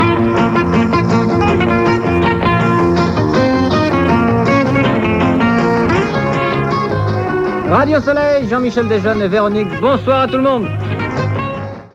Sintonia del programa i salutació inicial
Entreteniment
Espai estiuenc produït per Radio Luxembourg per als turistes, fet des dels estudis de Ràdio Barcelona.